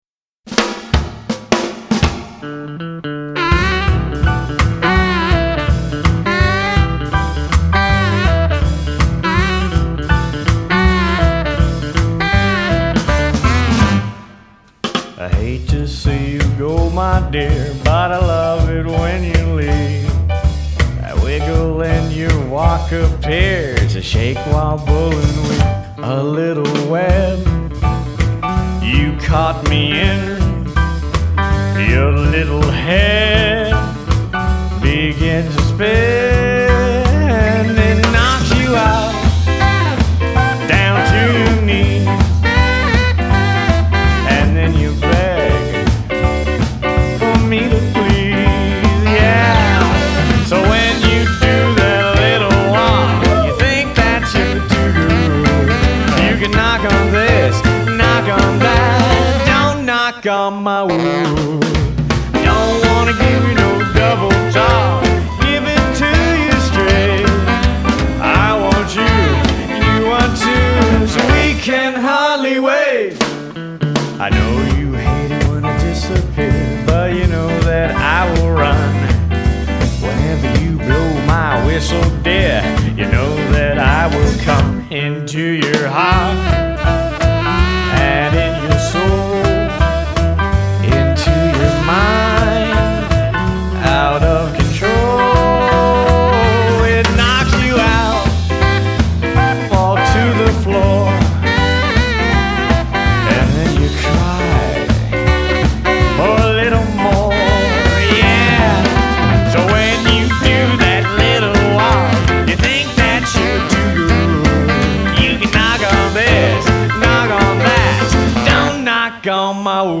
Vocals & Guitar
Bass & Vocals
Lead Guitar
Drums
Sax